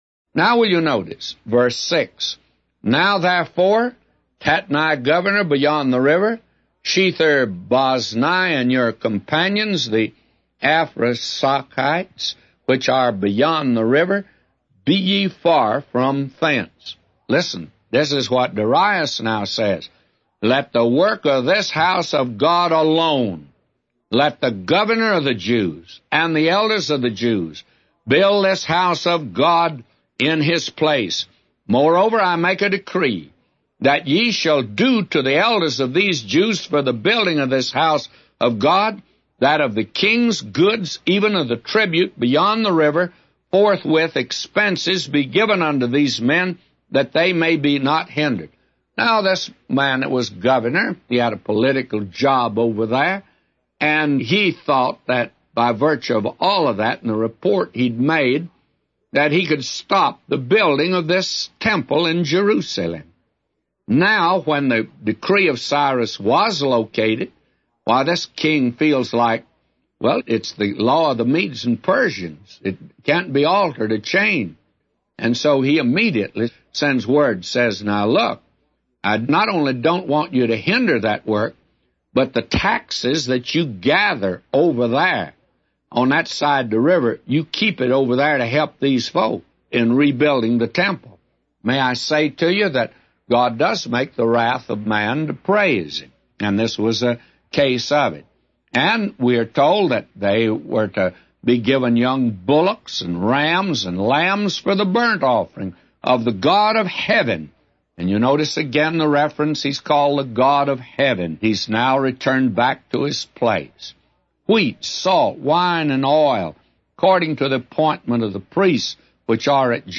A Commentary By J Vernon MCgee For Ezra 6:6-999